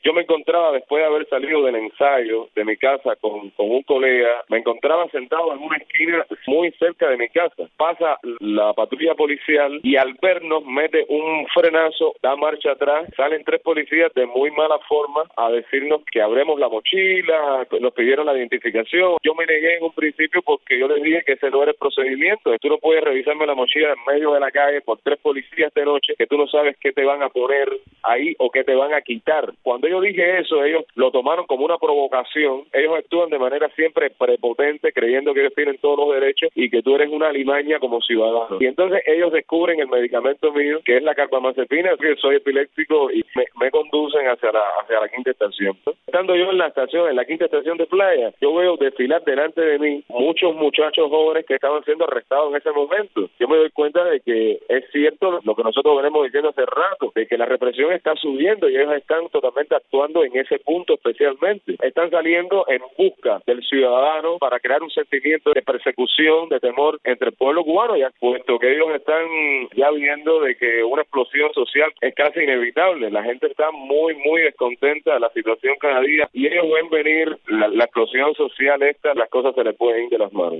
Declaraciones de Gorki Águila a Radio Martí